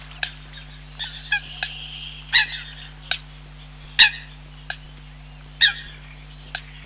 Eurasian Coot
Eurasian-Coot.mp3